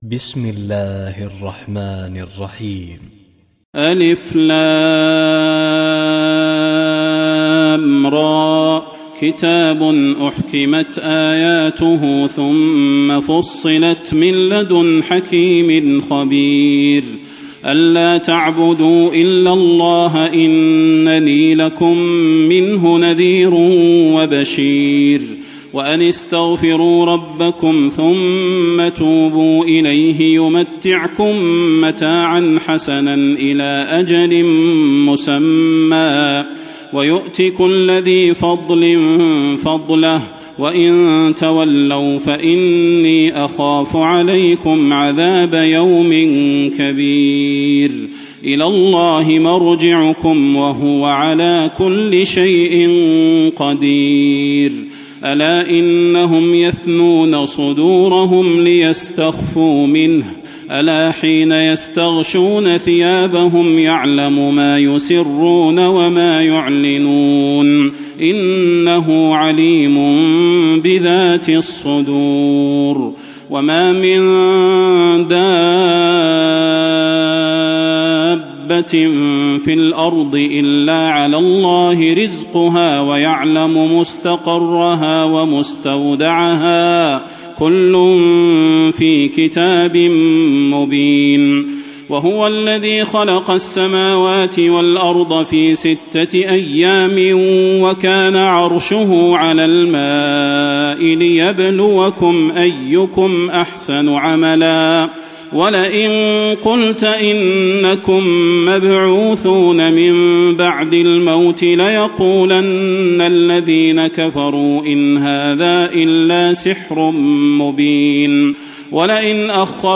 تراويح الليلة الحادية عشر رمضان 1435هـ من سورة هود (1-83) Taraweeh 11 st night Ramadan 1435H from Surah Hud > تراويح الحرم النبوي عام 1435 🕌 > التراويح - تلاوات الحرمين